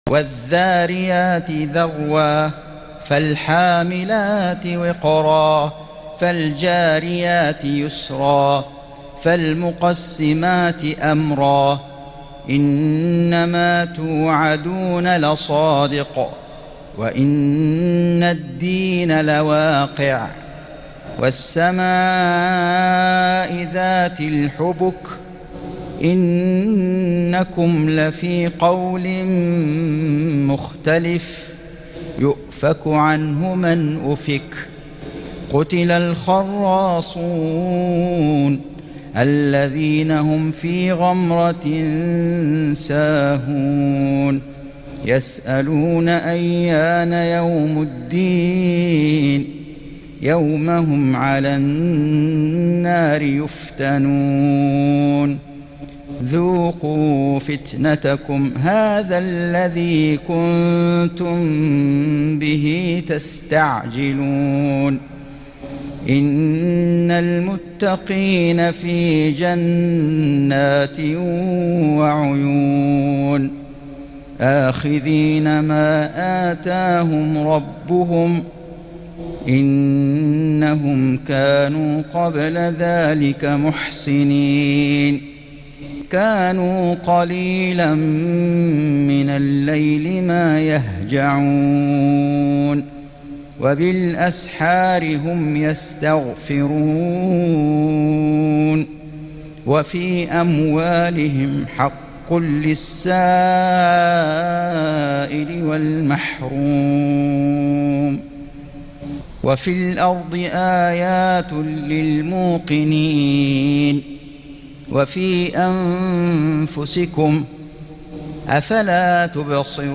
سورة الذاريات > السور المكتملة للشيخ فيصل غزاوي من الحرم المكي 🕋 > السور المكتملة 🕋 > المزيد - تلاوات الحرمين